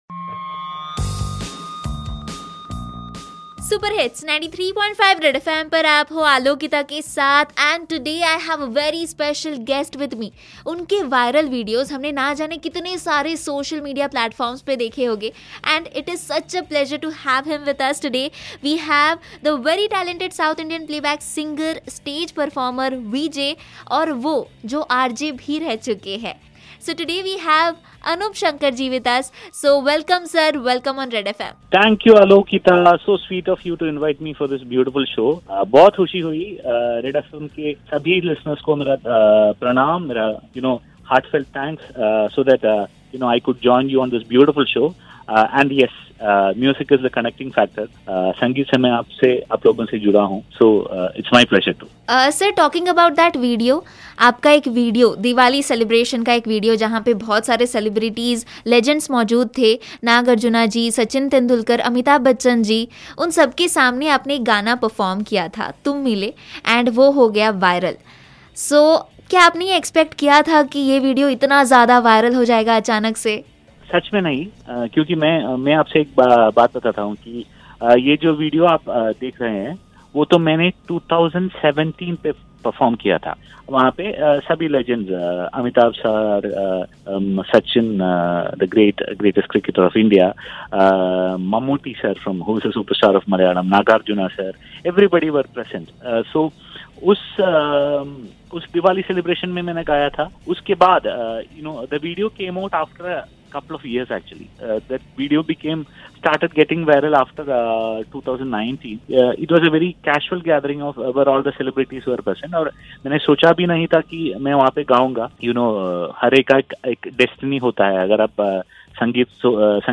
He is blessed with a mesmerizing voice.
unplugged version